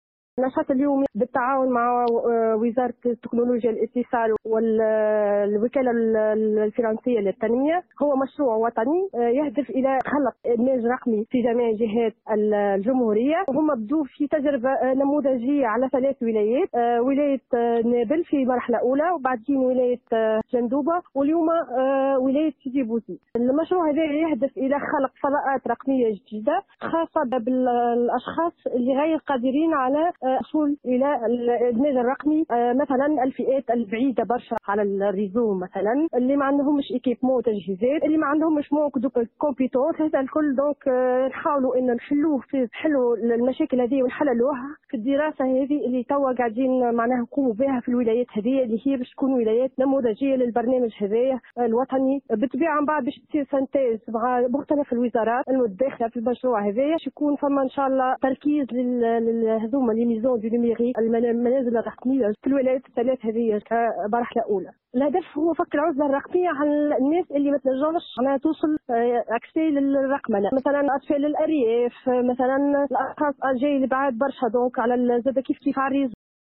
Sidi Bouzid: Atelier sur l’intégration numérique (Déclaration)